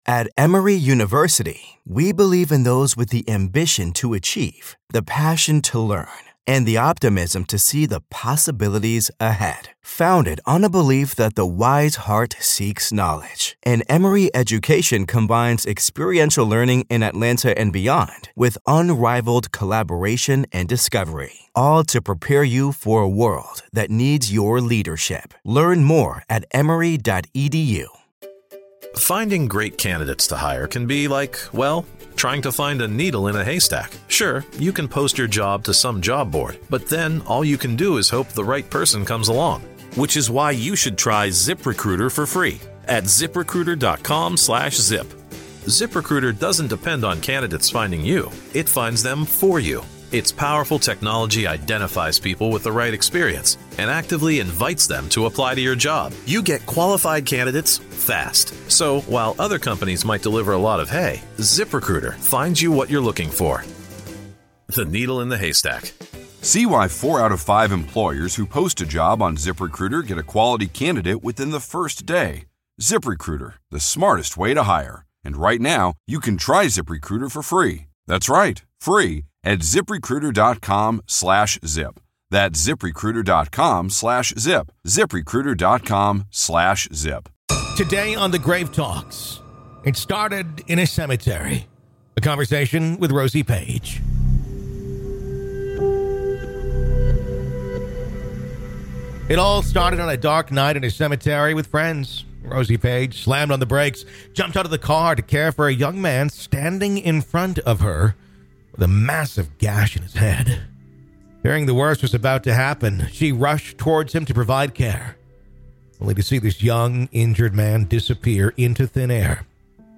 In Part One of our conversation, we dive into how one eerie encounter kicked off a lifetime of ghostly visits.